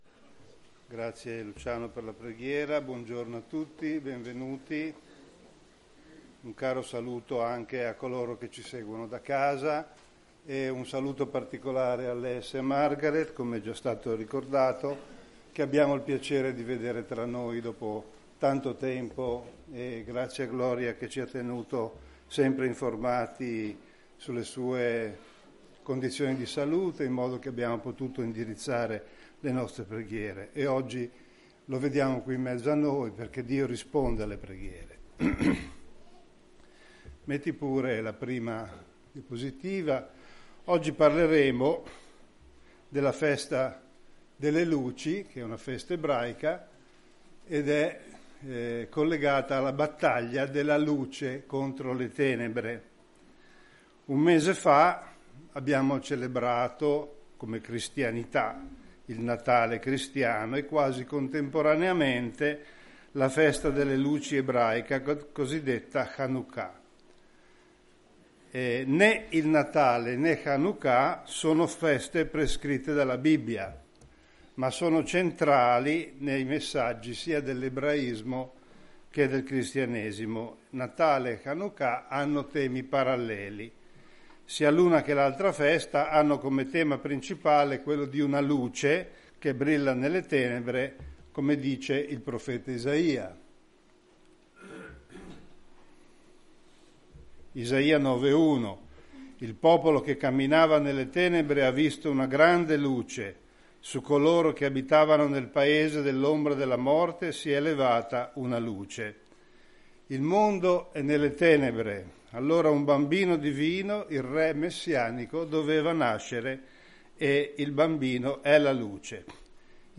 Israele | Sermon Topics | Chiesa Cristiana Evangelica - Via Di Vittorio, 14 Modena